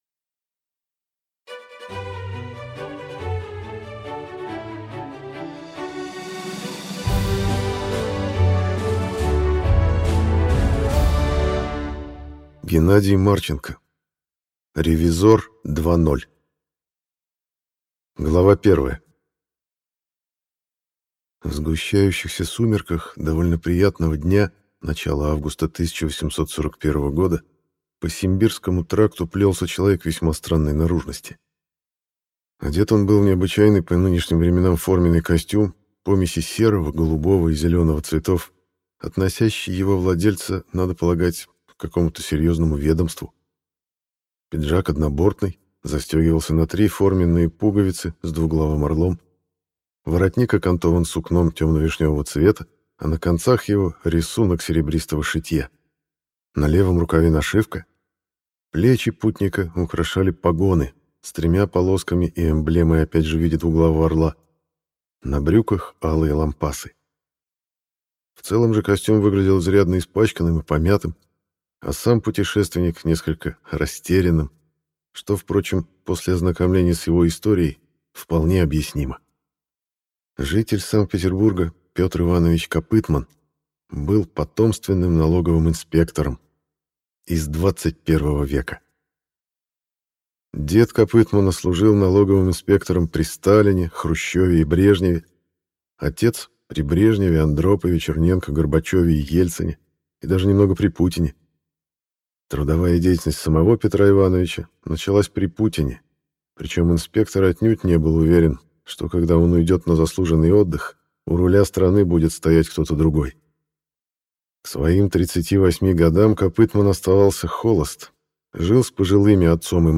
Аудиокнига Ревизор 2.0 | Библиотека аудиокниг